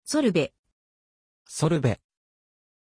Pronunciation of Solvey
pronunciation-solvey-ja.mp3